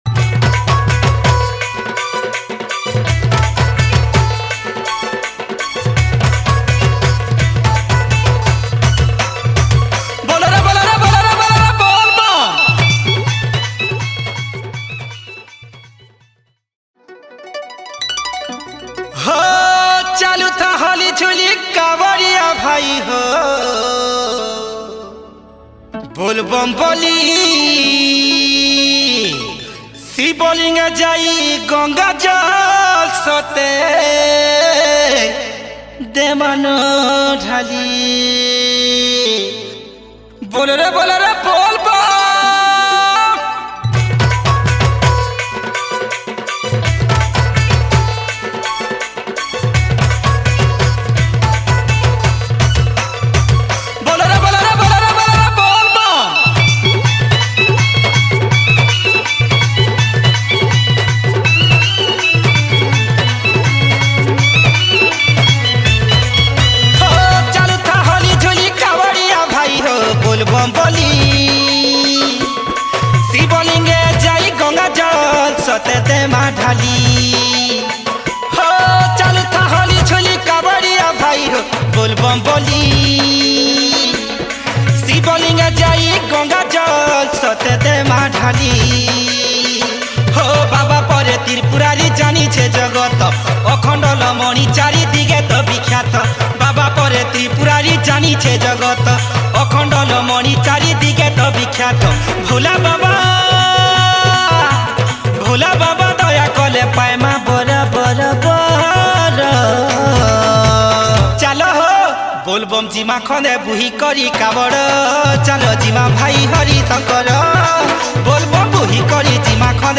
Bolbum Special Song Songs Download